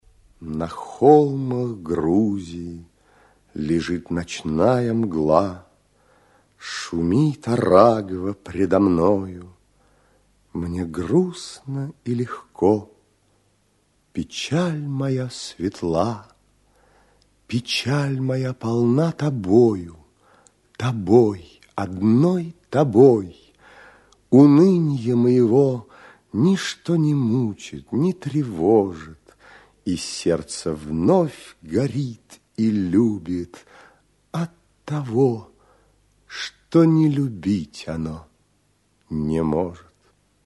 В 70-80 гг. на эстраде Козаков обычно выступал без музыкального сопровождения, но в записях музыка играет всё большую роль.